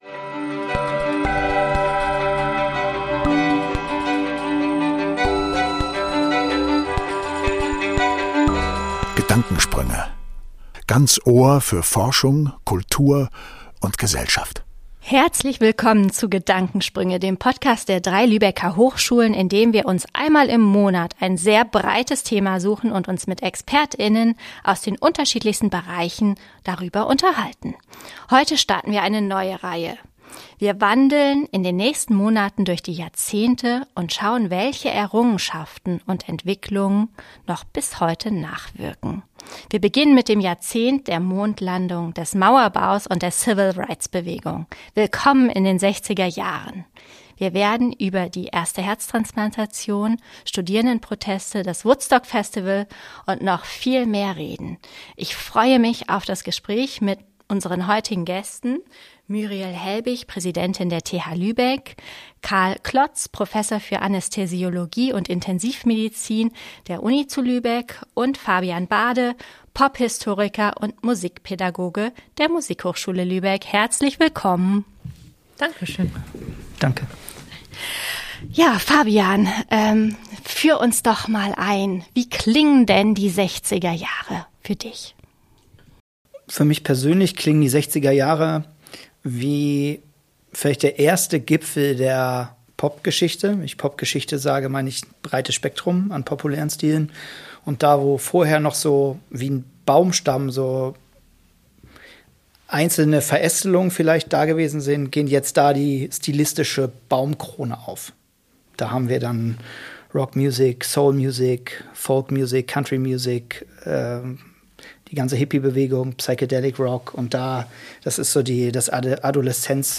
Mit diesen Fragen gehen wir in unsere 44. Folge und starten unseren Rückblick in den 1960er Jahren. Es diskutieren eine Hochschulpräsidentin, ein Änästhesist und ein Musikwissenschaftler.